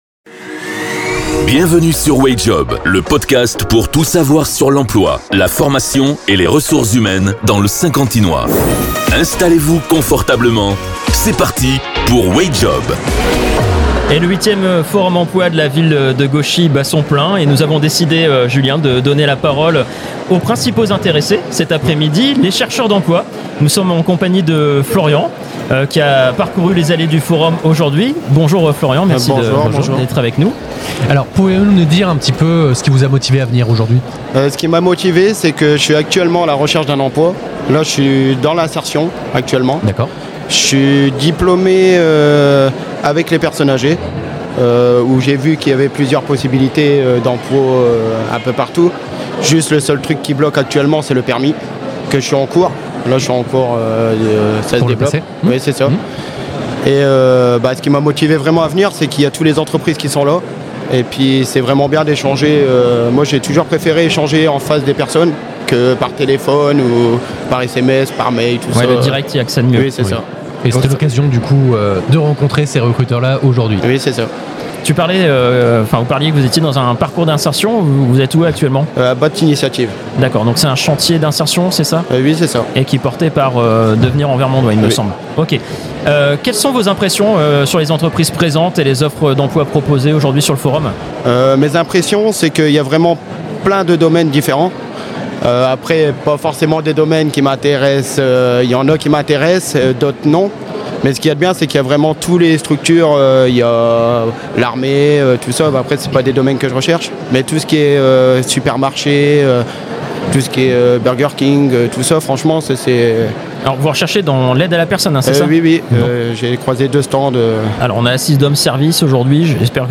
Un témoignage authentique qui met en lumière l'impact direct de ces événements sur les demandeurs d'emploi et les opportunités locales dans le Saint-Quentinois.